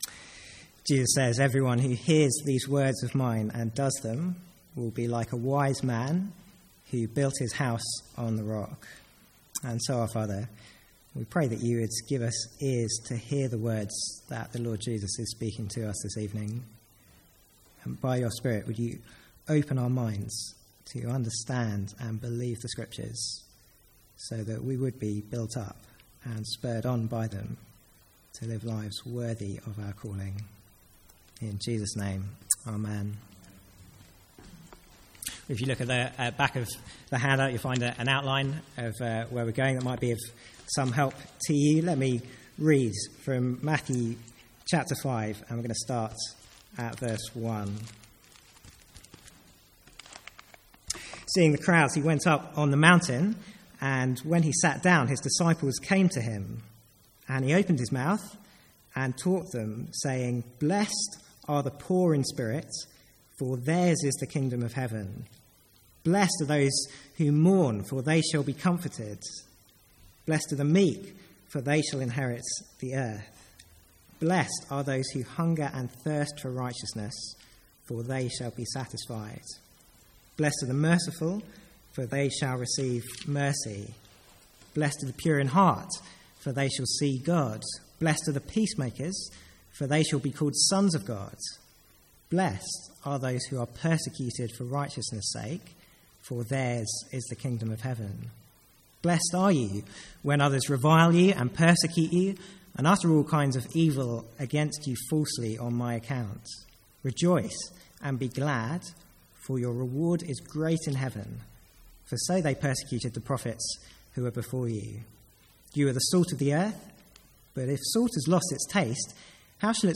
Sermons | St Andrews Free Church
From the Sunday evening series 'Hard sayings of Jesus'.